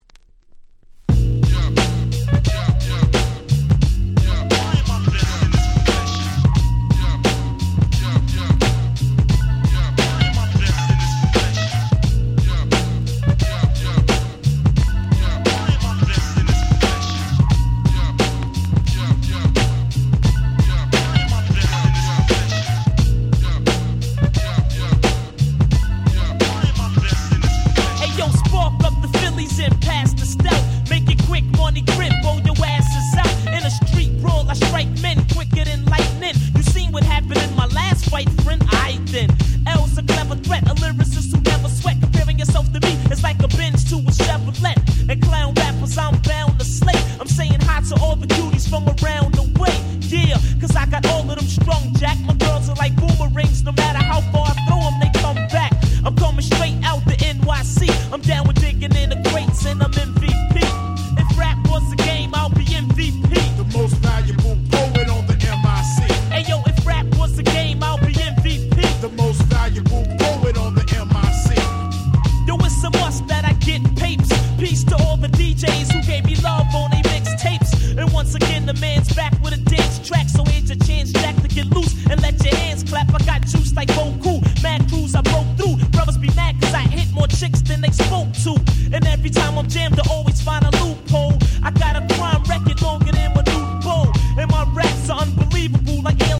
Jazzyなネタ使いの超格好良いRemixでございます！